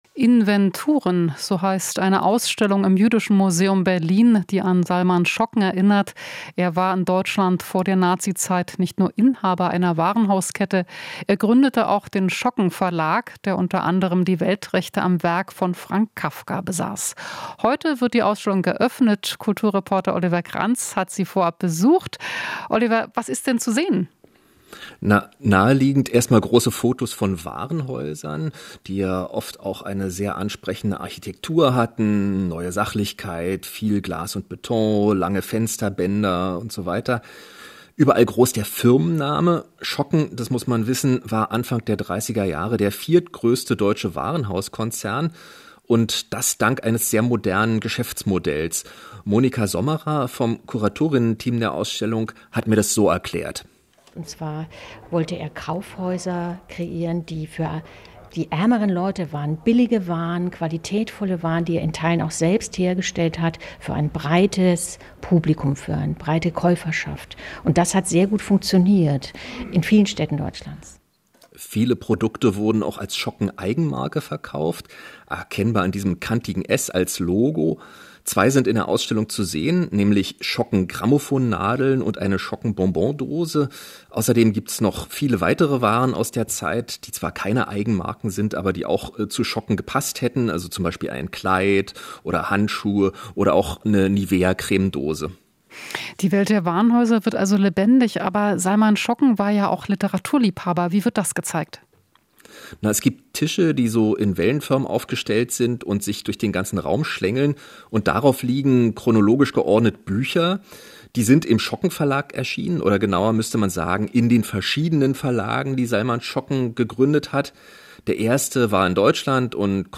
Interview - Ausstellung zu Salman Schocken eröffnet